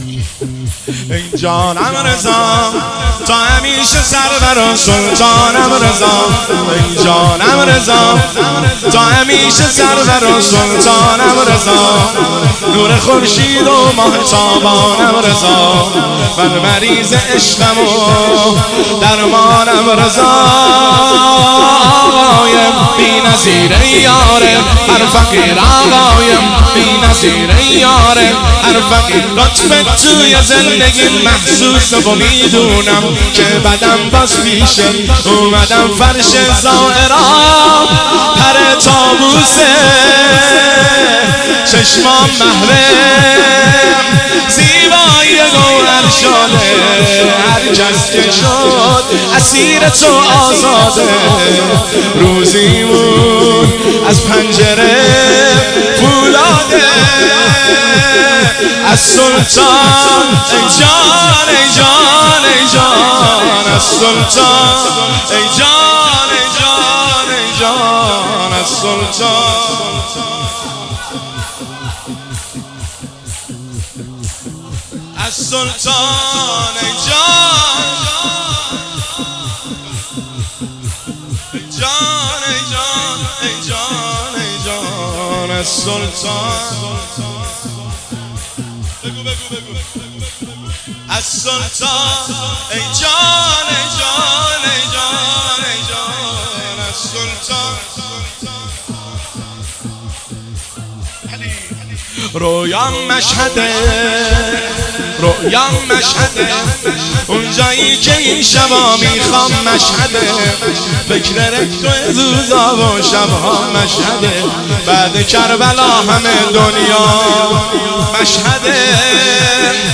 شور شب 30 صفرالمظفر 1402